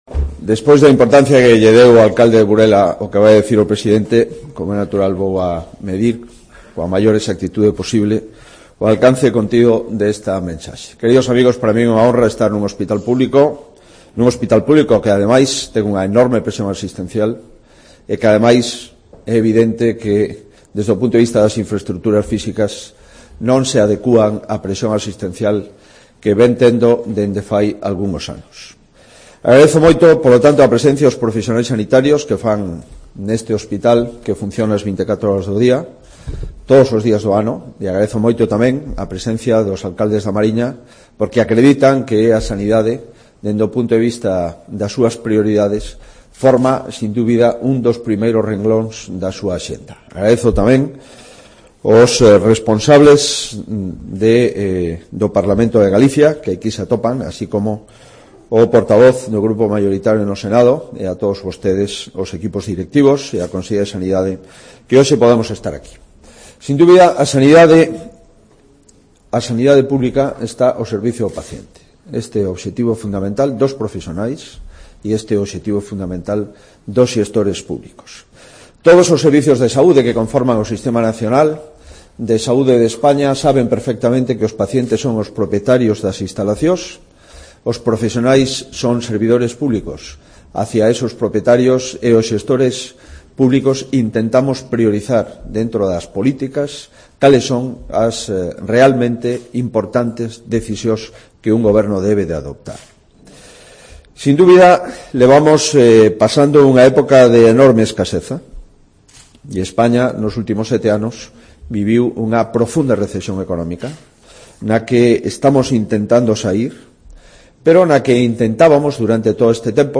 Alberto Núñez Feijóo (Presentación Plan Director Hospital da Costa 18-03-15)